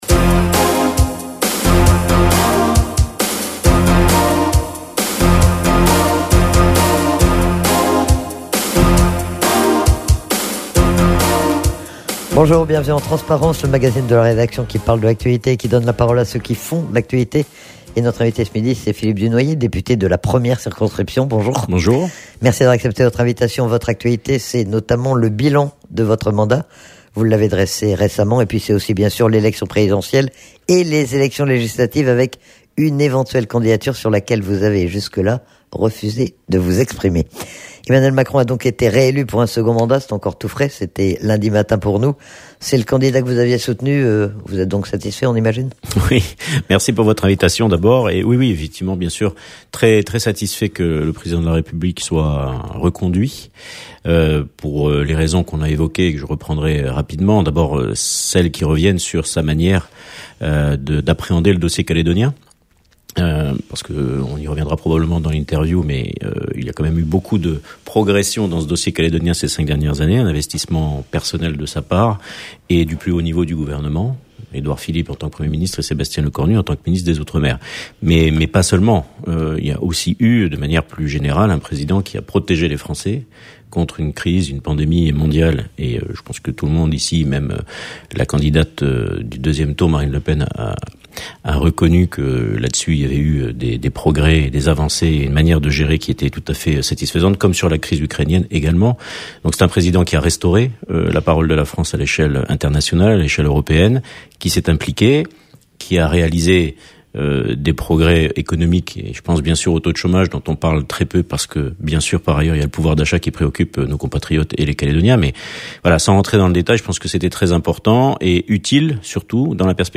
Le député de la première circonscription est interrogé sur le bilan de son mandat mais aussi sur l'actualité politique et notamment sur le résultat de l'élection présidentielle et sur la campagne législative.